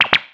《プニョッなシステム音２》フリー効果音
プニョッという感じの効果音。システム音やプニョッとした時に。